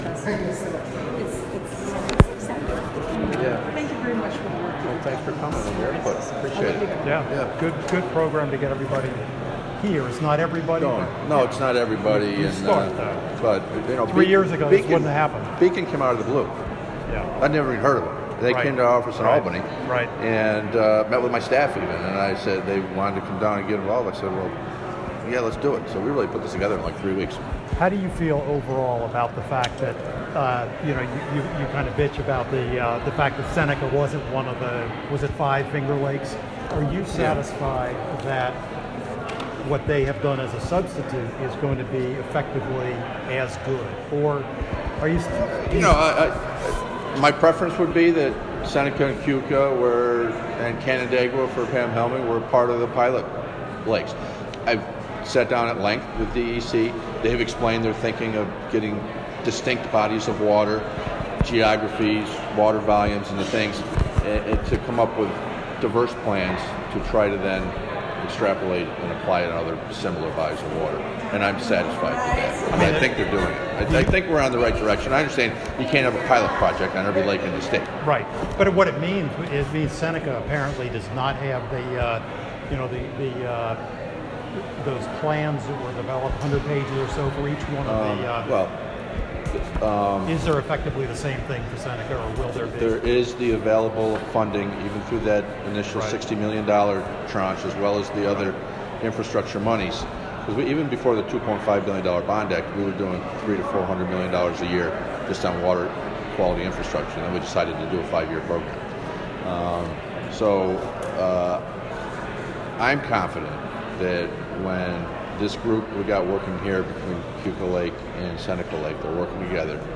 interview Oct. 24.